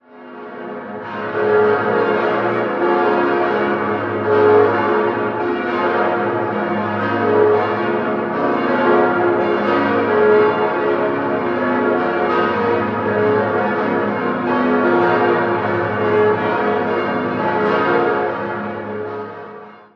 9-stimmiges Geläute: f°-as°-b°-des'-es'-f'-as'-b'-c''
Monumentales Großgeläute und das tontiefste in Luxemburg.